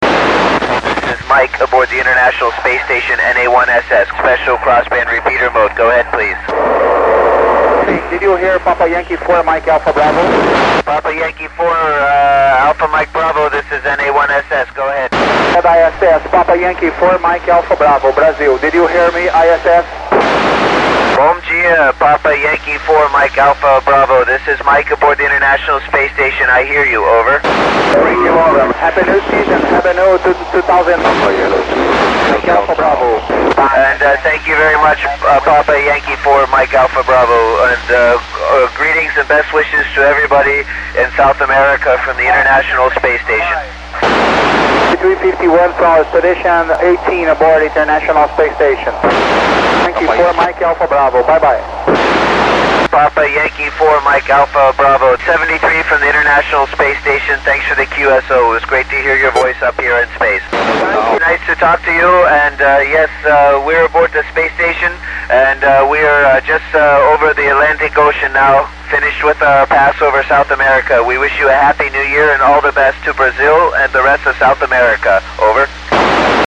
cull contact with mike finke onbord iss on december 28 2008.